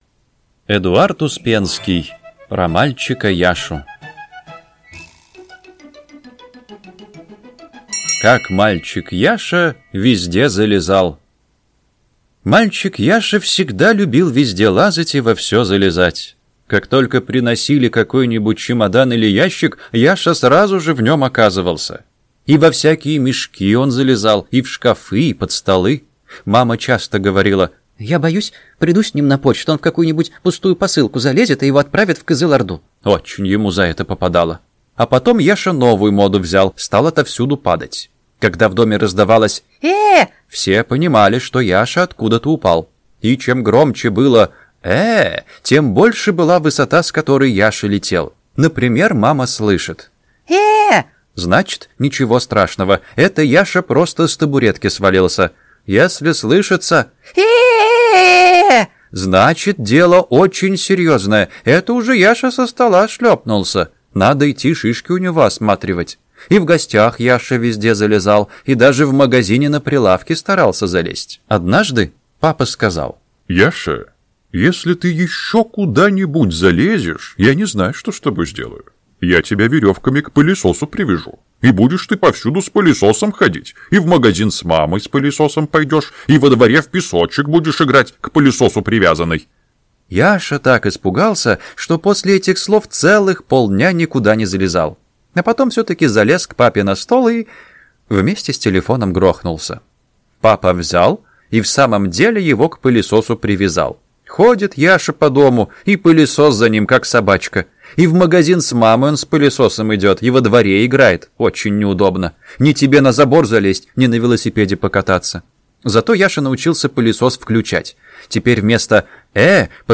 Аудиокнига Смешные рассказы для детей | Библиотека аудиокниг